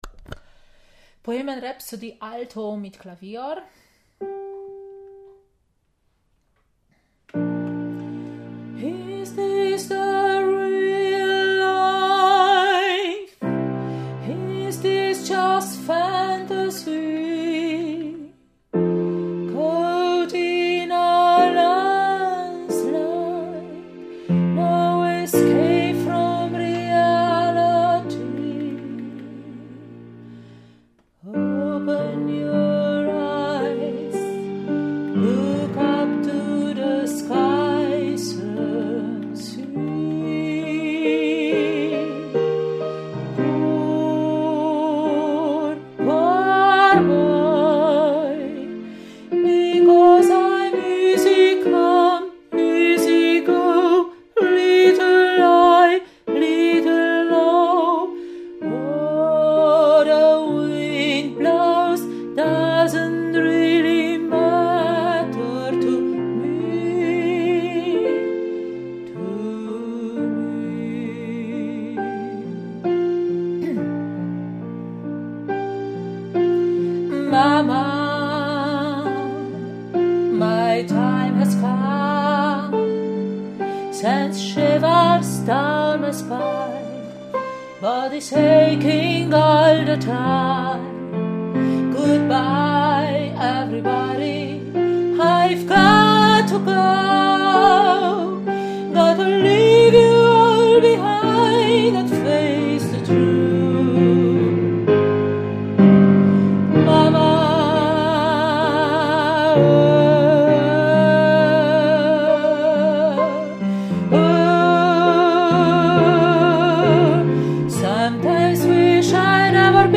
Bohemian Rhapsody – Alto mit Klavier